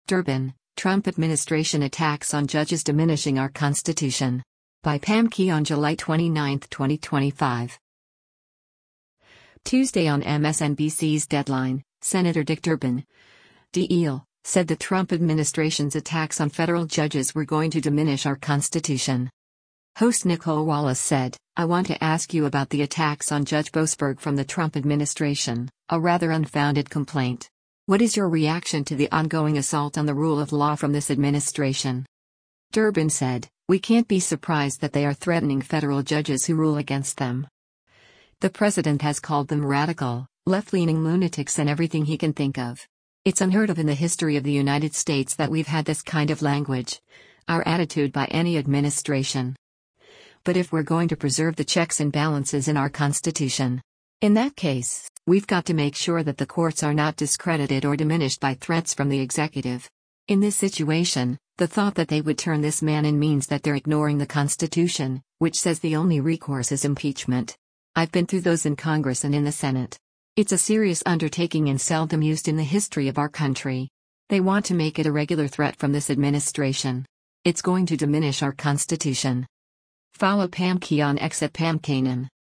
Tuesday on MSNBC’s “Deadline,” Sen. Dick Durbin (D-IL) said the Trump administration’s attacks on federal judges were “going to diminish our Constitution.”
Host Nicolle Wallace said, “I want to ask you about the attacks on Judge Boasberg from the Trump administration, a rather unfounded complaint.